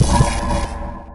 anomaly_gravy_blast.ogg